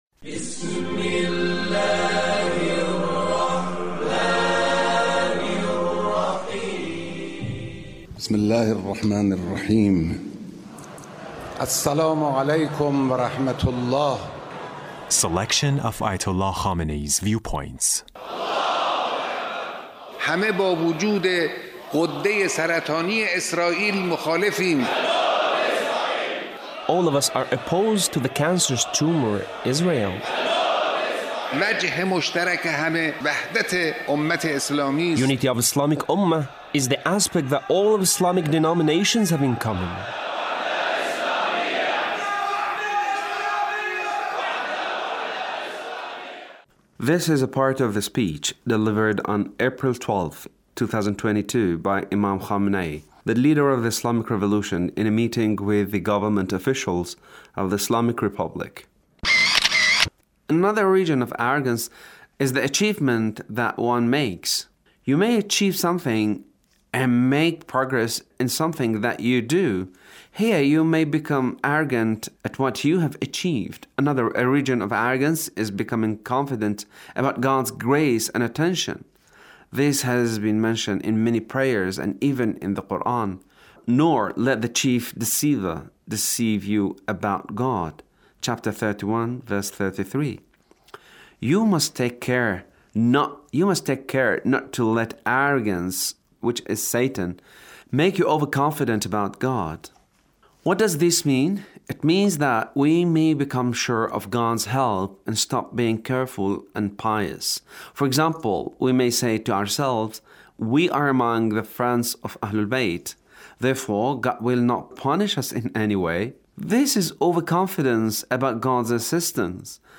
Leader's speech (1379)